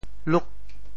“矞”字用潮州话怎么说？